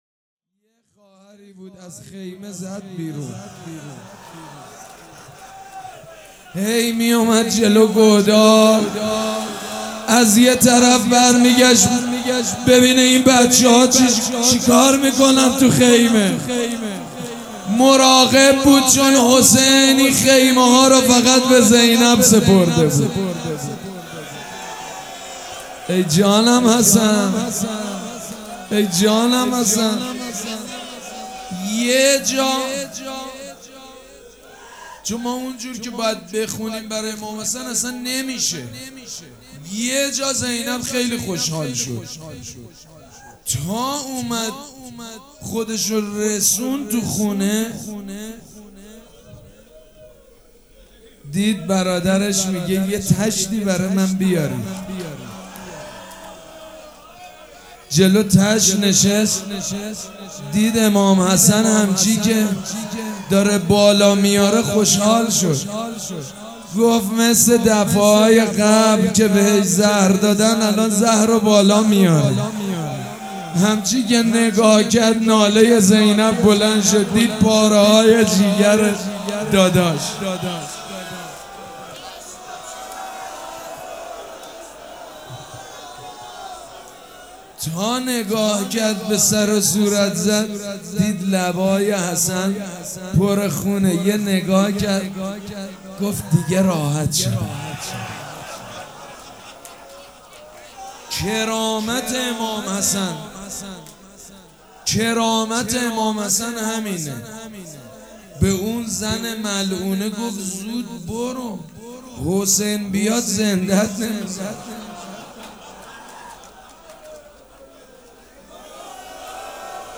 روضه بخش چهارم
حاج سید مجید بنی فاطمه شنبه 13 مهر 1398 هیئت ریحانه الحسین سلام الله علیها
سبک اثــر روضه مداح حاج سید مجید بنی فاطمه
مراسم عزاداری شب پنجم